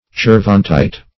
Search Result for " cervantite" : The Collaborative International Dictionary of English v.0.48: Cervantite \Cer"van*tite\, n. [Named from Cervantes a town in Spain.]